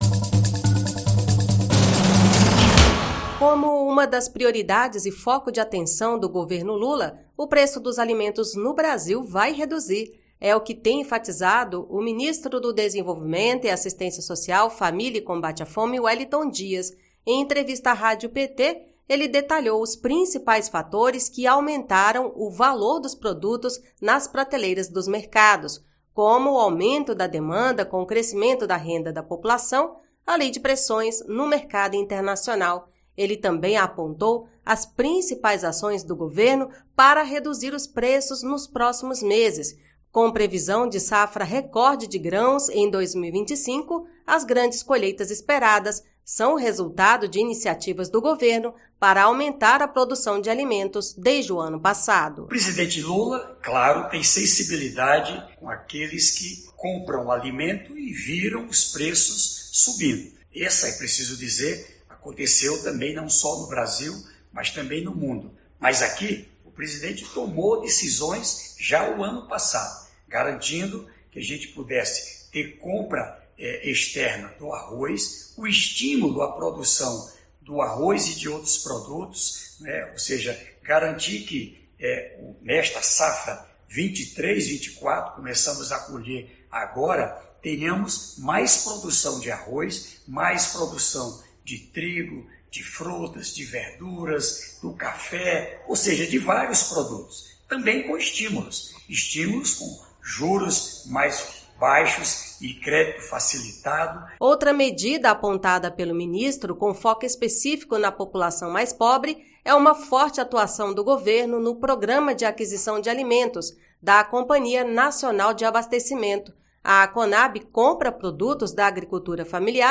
Em entrevista, o ministro Wellington Dias destacou que ações como o Programa de Aquisição de Alimentos terão impacto positivo na economia e nos valores dos produtos.